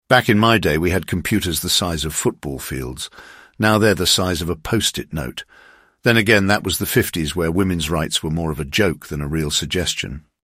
oh yeah old British accent go brrrrr